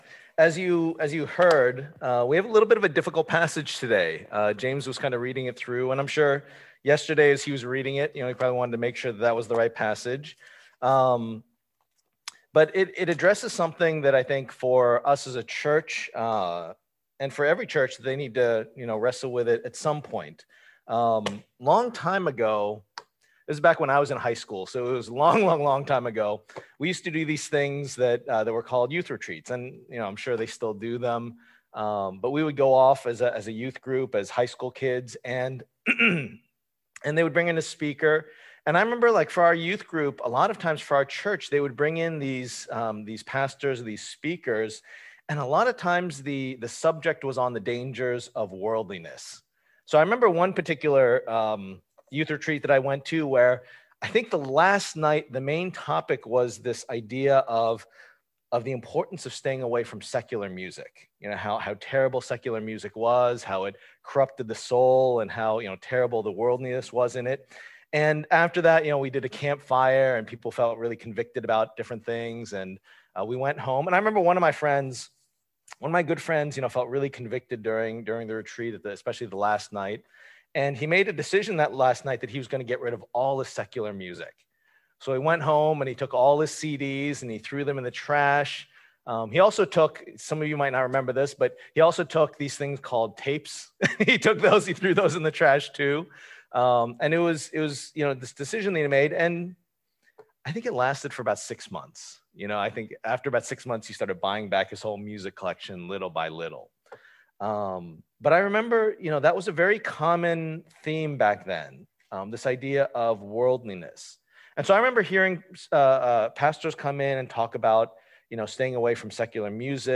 Passage: 1 Corinthians 5:1-13 Service Type: Lord's Day